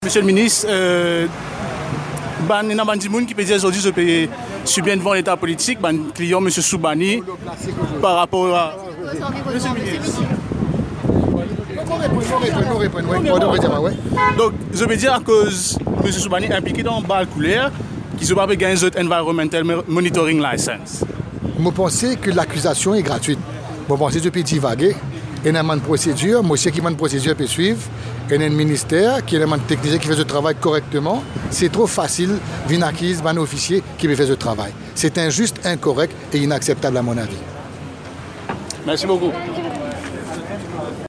Finalement, lors d’une cérémonie, ce mercredi 18 juillet, à China Town, le ministre de l’Environnement a réagi. Interrogé sur le fait que le projet de Gros-Caillou n’obtient pas d’Environmental Monitoring Licence, celui-ci a répondu : «Zot pé divagé.